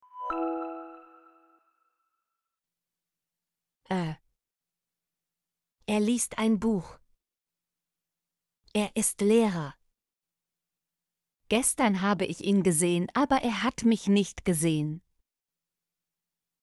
er - Example Sentences & Pronunciation, German Frequency List